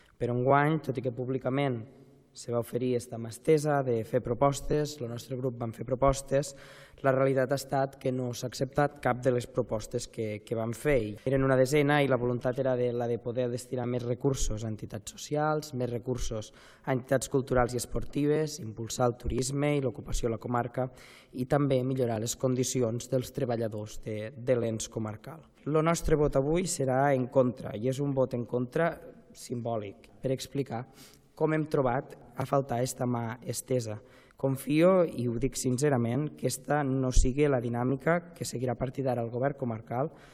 El pressupost s’ha aprovat amb els vots a favor dels dos grups de govern, ERC i PSC, i el vot en contra de l’oposició de Junts, on el seu portaveu, Òscar Ologaray ha recriminat que no s’hagi inclòs als comptes de l’any que ve cap de les propostes presentades per un valor global de 200.000 euros…